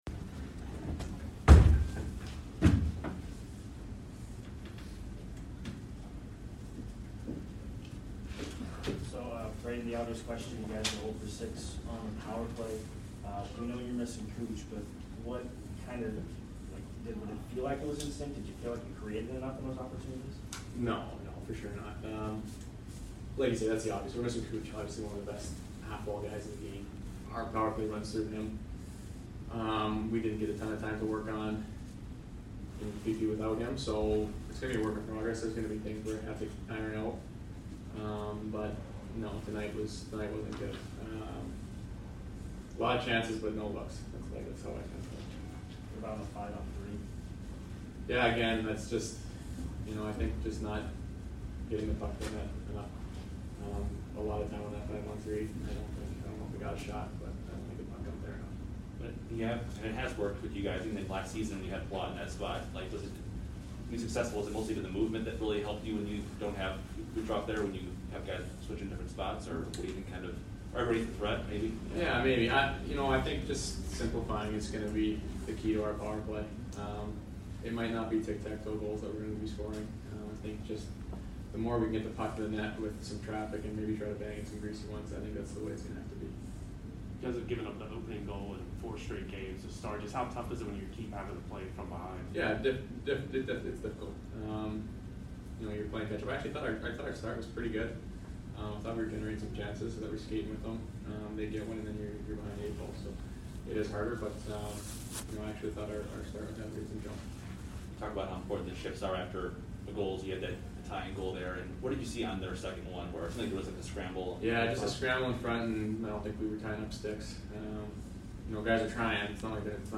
Brayden Point Postgame VS FLA 10 - 19 - 21